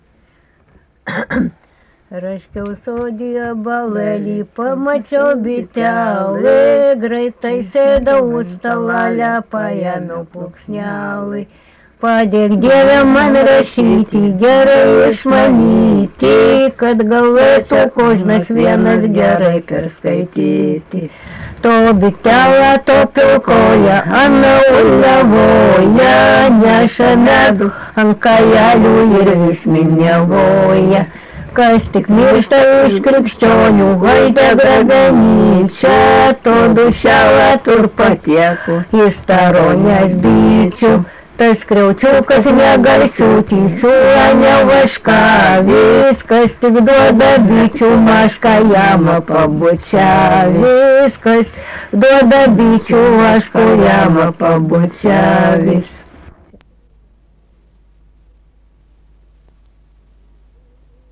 daina
Krapiškis
vokalinis